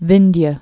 (vĭndyə)